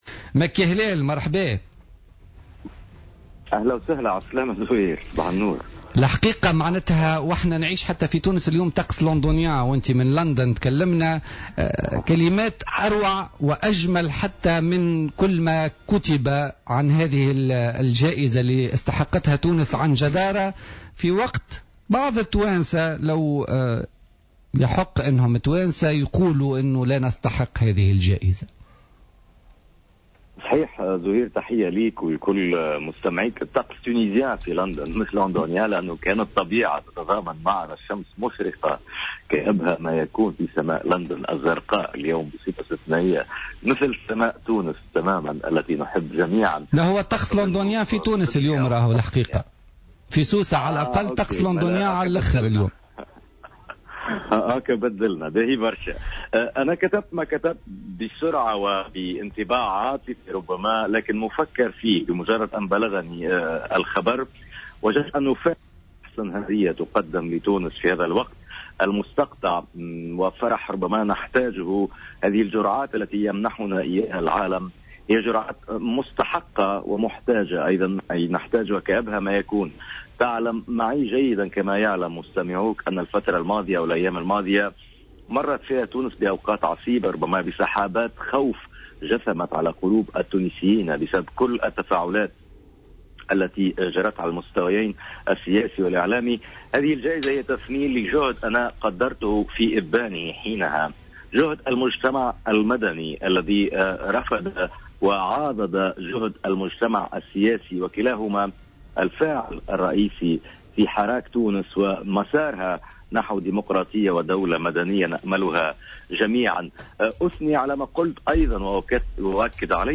مداخلة له في برنامج بوليتيكا